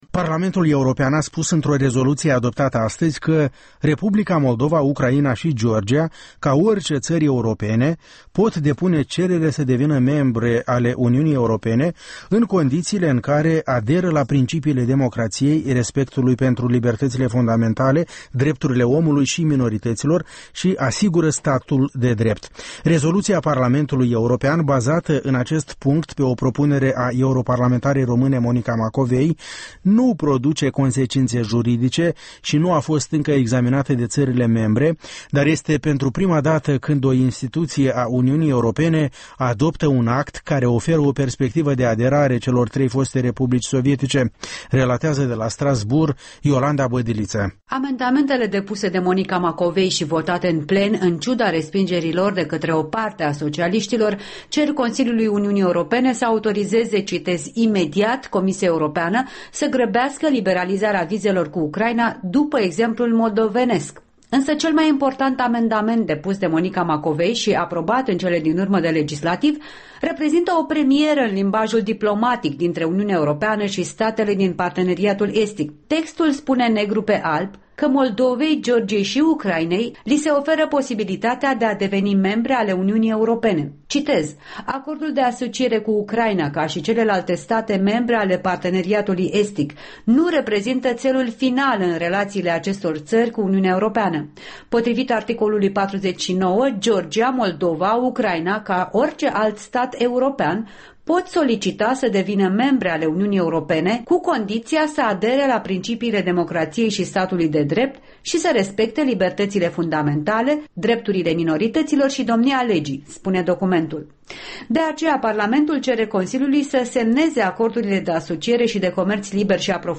În direct de la Strasbourg